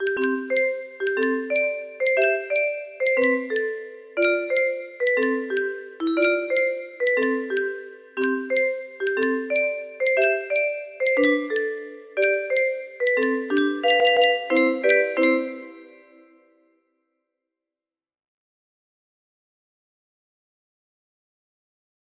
30107 music box melody